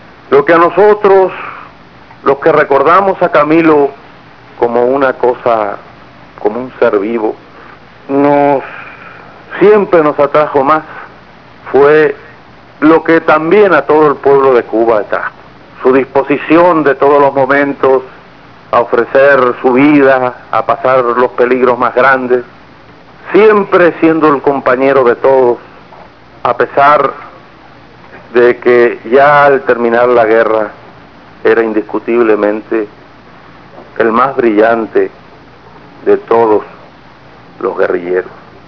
Frammento di un discorso di "Che" Guevara su "Camilo"
discurso.wav